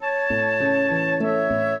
flute-harp
minuet10-12.wav